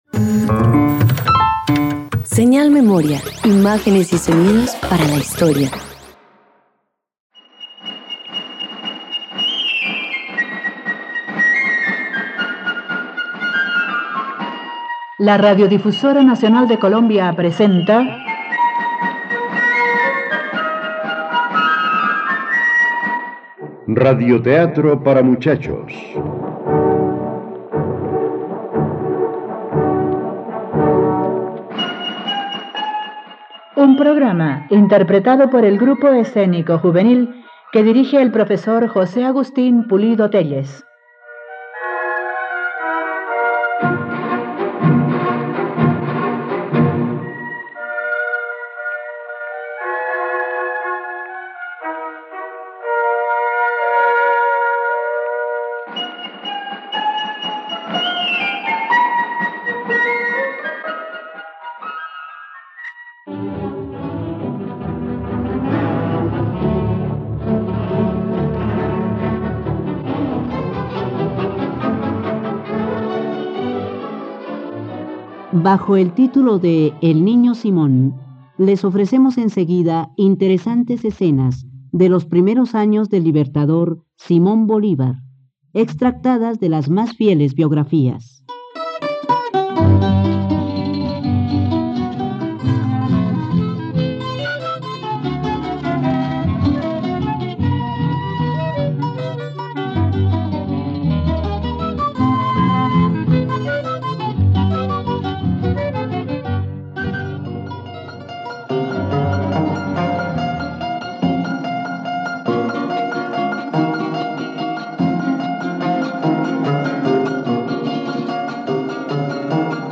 El niño Simón - Radioteatro dominical | RTVCPlay